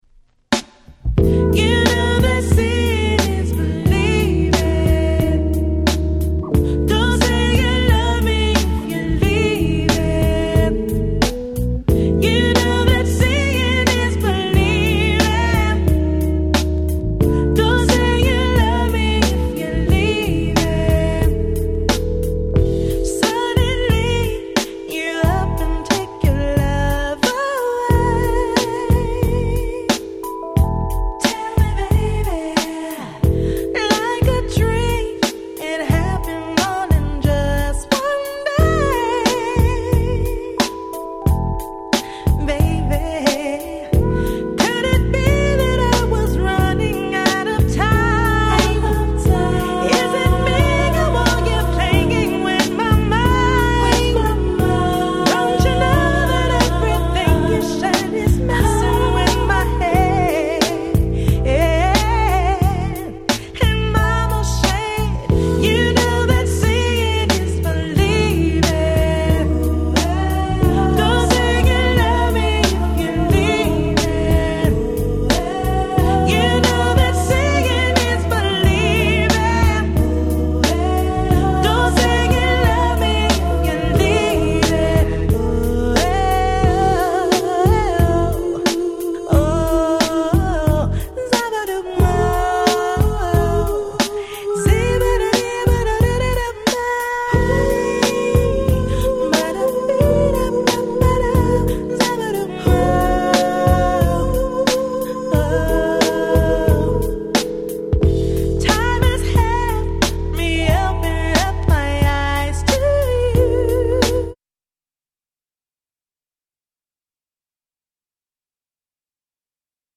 Nice Hip Hop Soul♪
Originalとはまた違った使い方の出来るNeo Soul的に格好良いRemixってことですな。